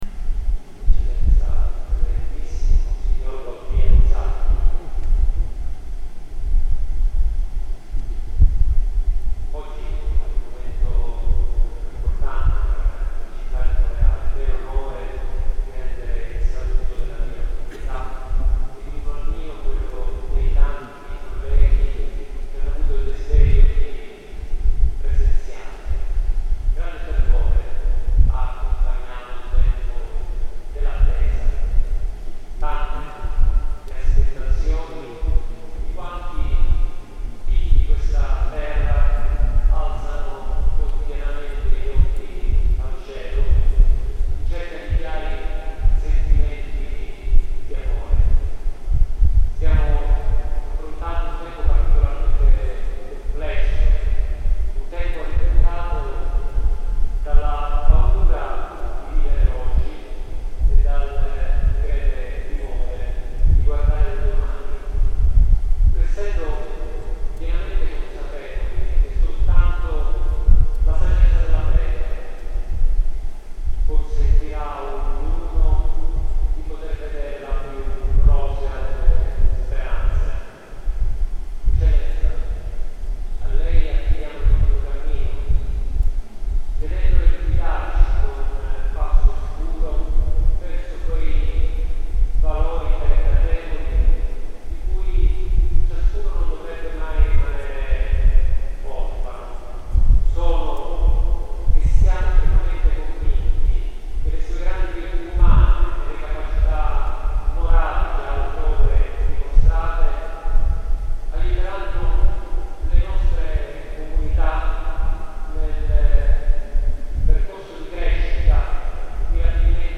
Gallery >> Audio >> Audio2022 >> Ordinazione Arcivescovo Isacchi >> 23-Sindaco OrdinazArcivescovo 31Lug22
23-Sindaco OrdinazArcivescovo 31Lug22